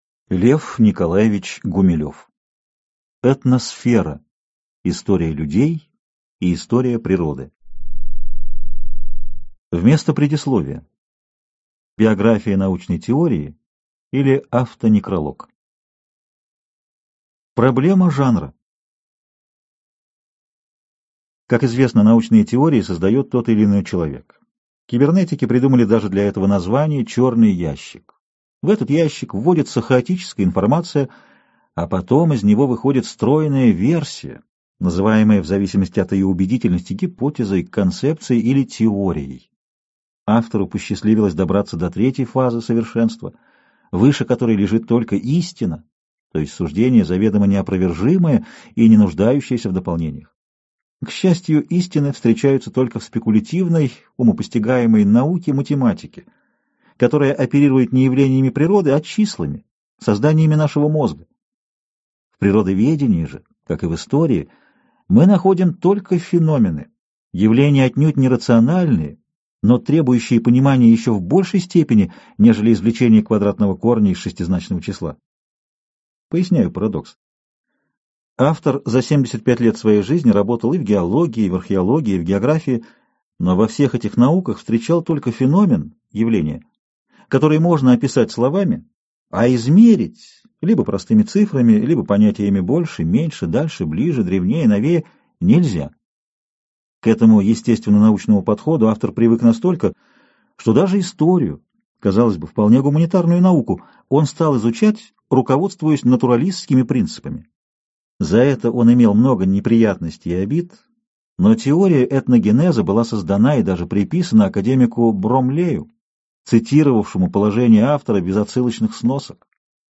Аудиокнига Этносфера. История людей и история природы | Библиотека аудиокниг